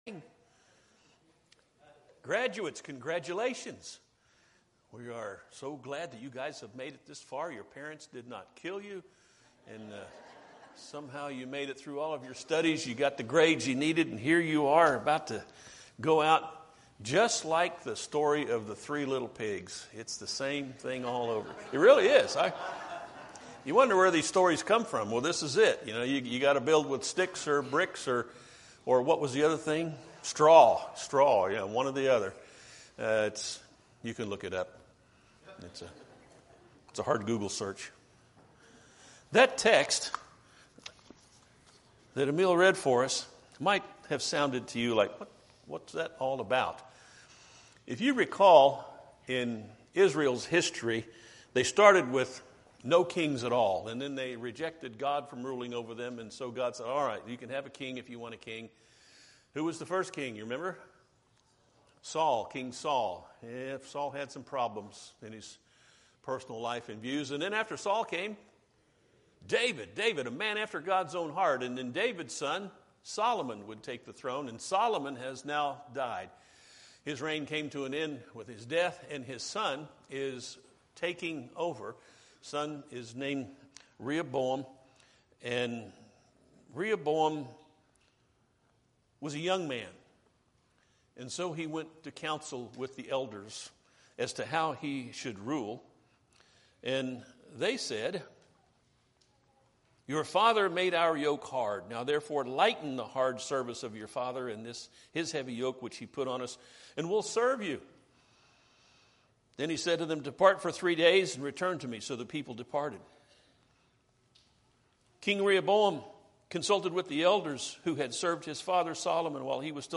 Series Sermons What Can a Young Person Do?